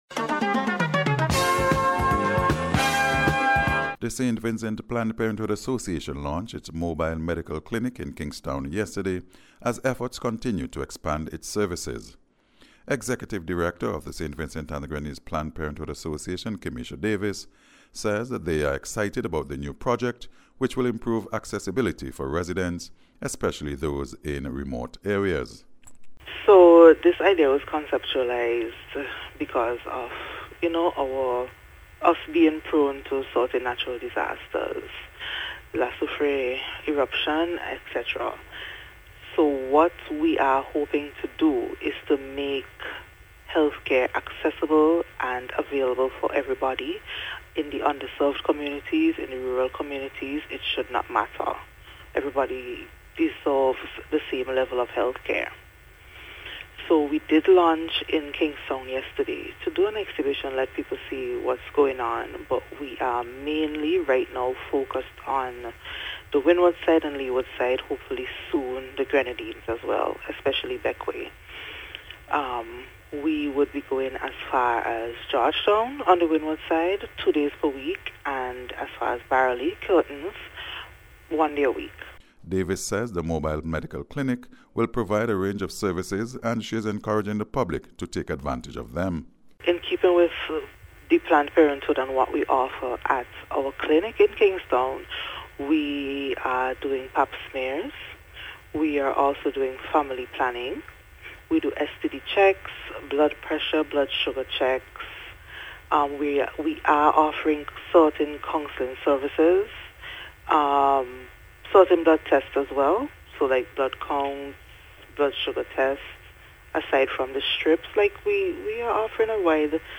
NBC’s Special Report- Thursday 7th July,2025
SVPPA-MOBILE-CLINIC-REPORT.mp3